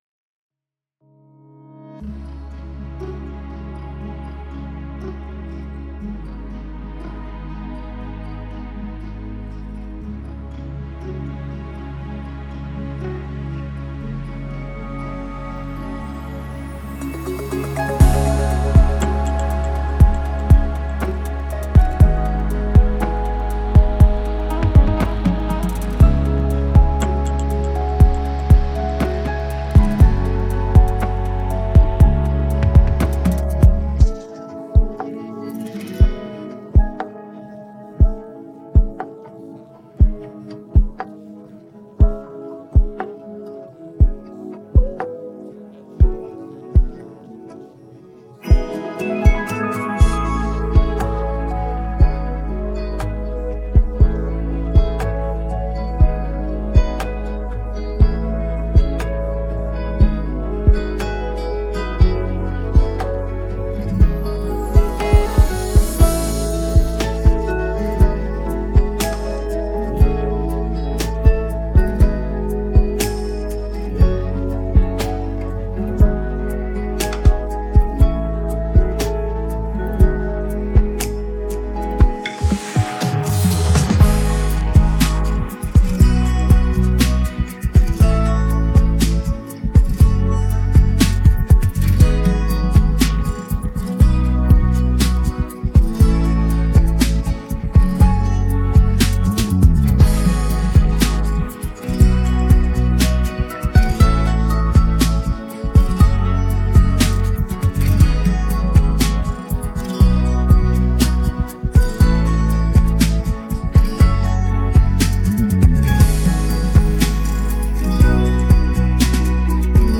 بیت بدون صدا خواننده